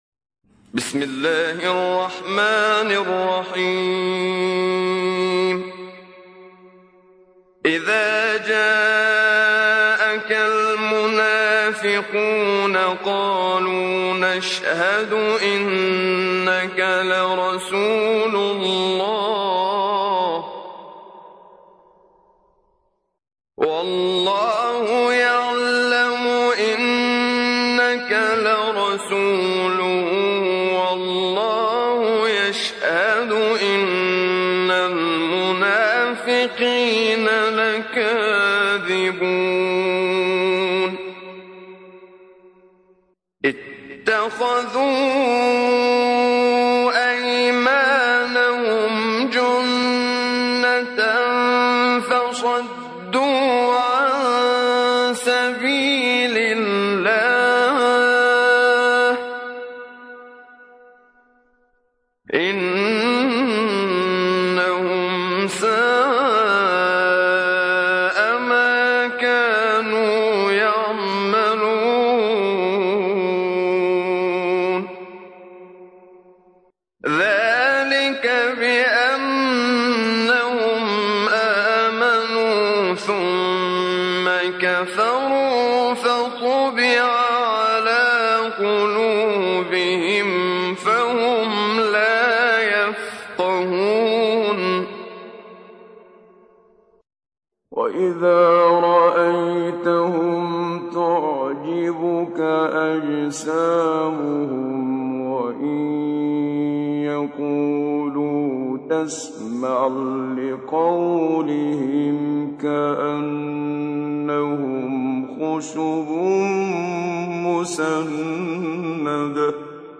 تحميل : 63. سورة المنافقون / القارئ محمد صديق المنشاوي / القرآن الكريم / موقع يا حسين